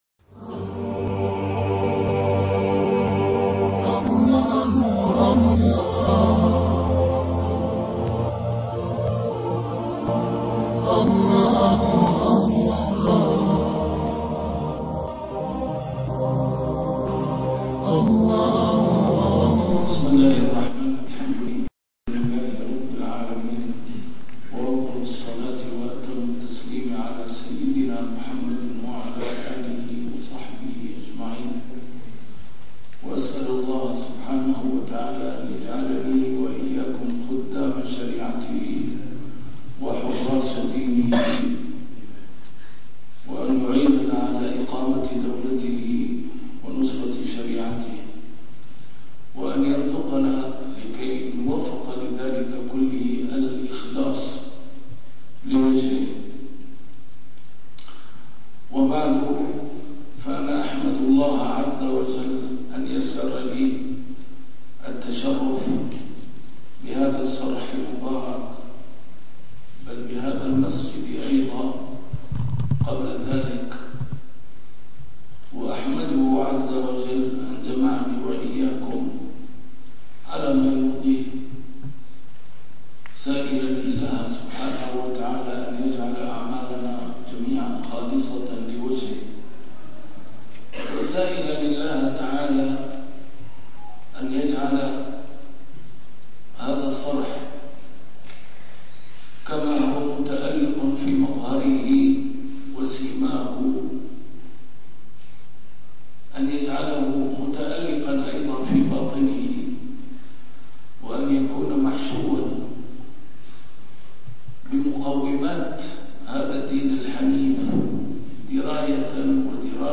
نسيم الشام › A MARTYR SCHOLAR: IMAM MUHAMMAD SAEED RAMADAN AL-BOUTI - الدروس العلمية - محاضرات متفرقة في مناسبات مختلفة - محاضرة العلامة الشهيد لطلبة العلم في معهد بدر الدين الحسني